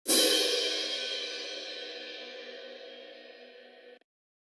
OpenHat Wolf (2).wav